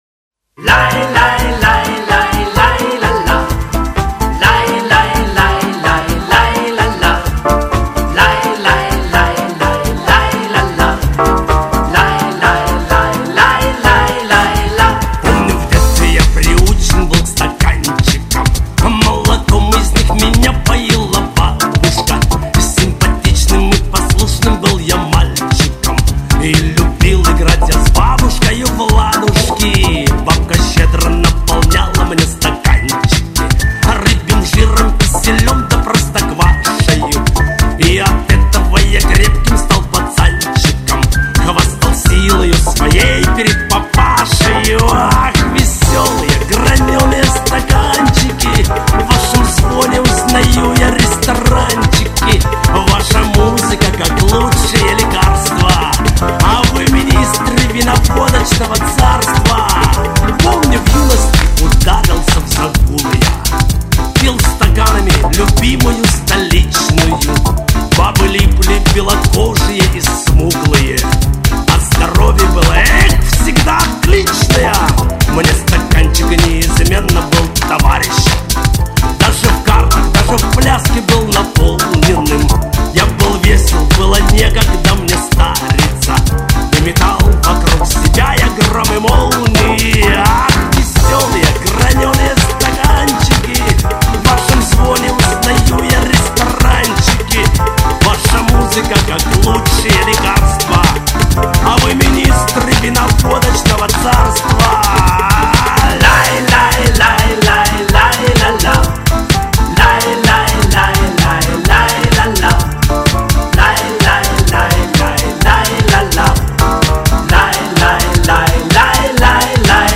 Тоже в новой аранжировке.